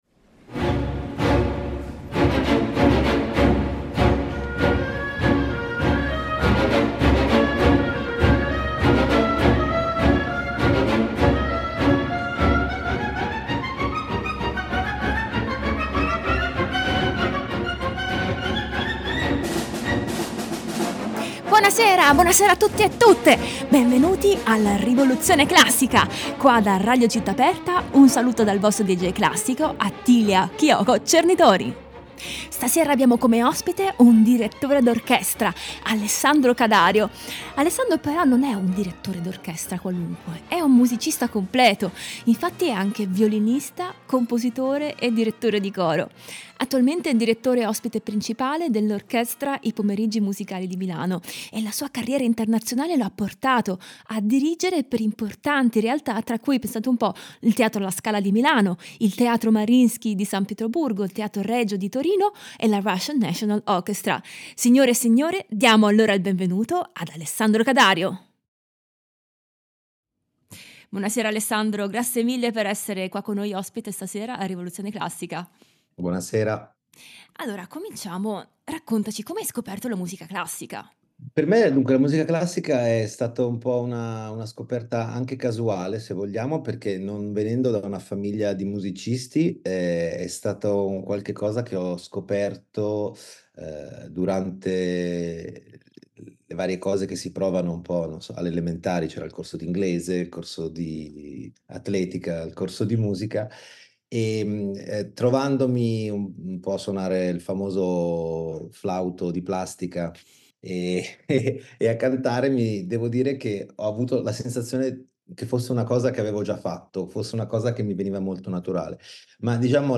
Ospite di questa puntata il direttore d’orchestra